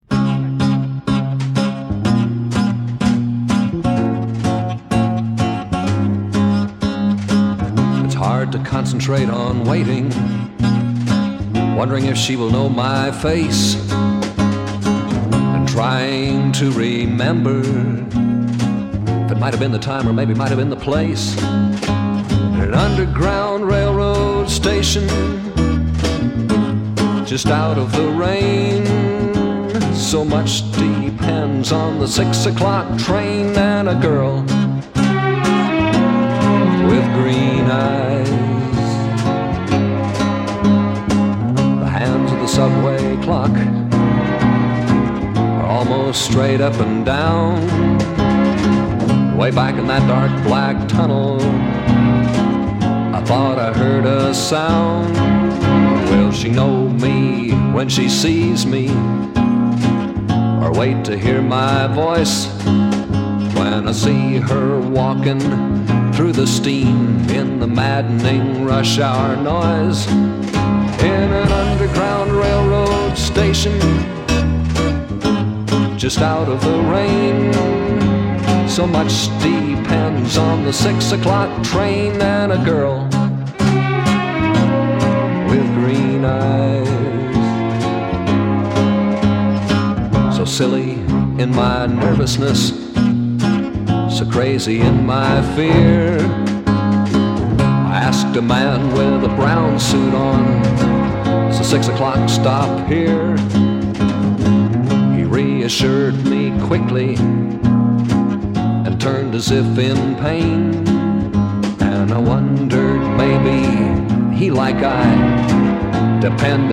Lovely folk pop 2-sider